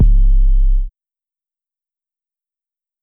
808 (Early 20 Rager).wav